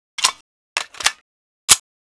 c96_worldreload.wav